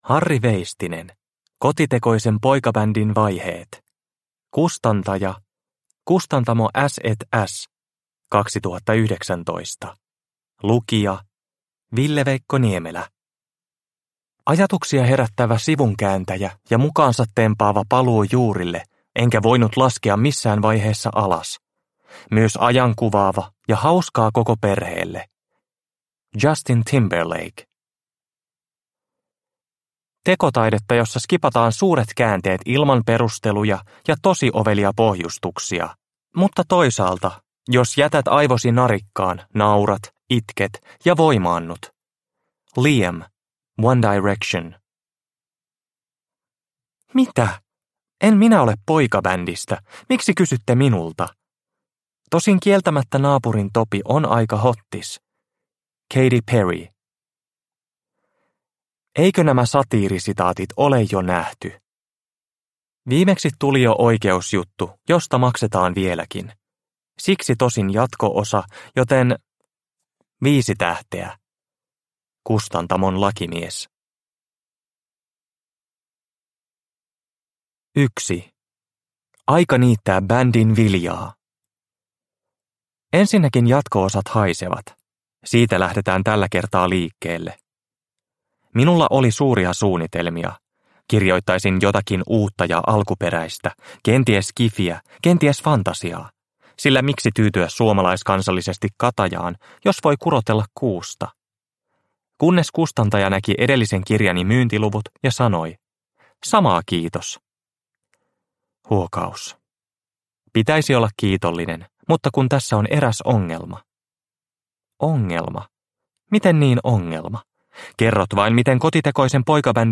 Kotitekoisen poikabändin vaiheet – Ljudbok – Laddas ner